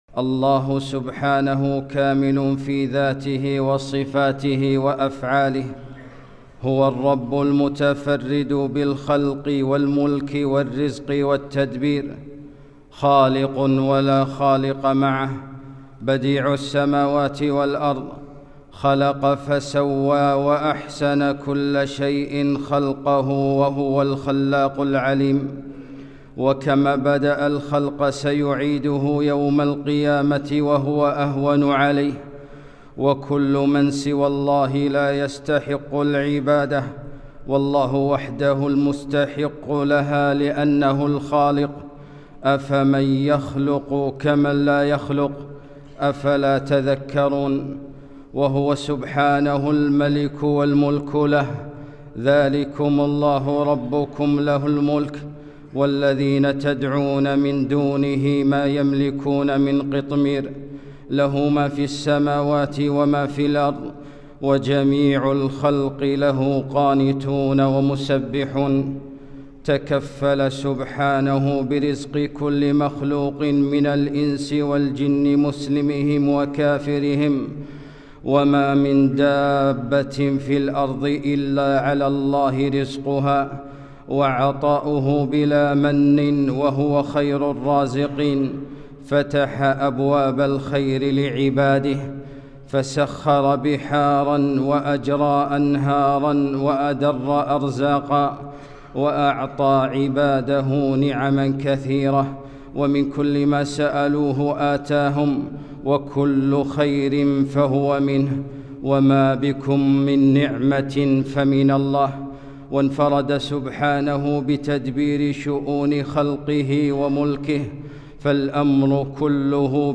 خطبة - الخالق المالك المدبر